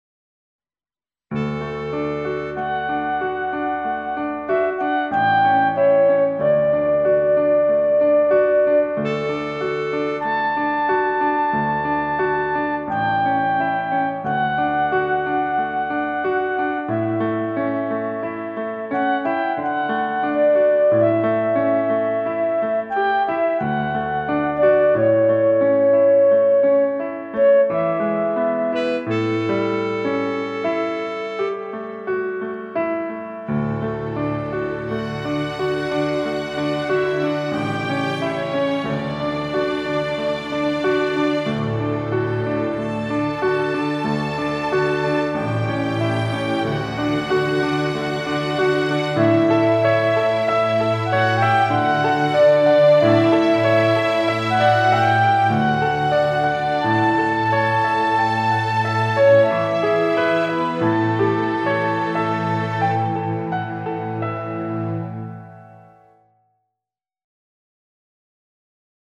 Largo [40-50] nostalgie - clarinette - hiver - neige - sapin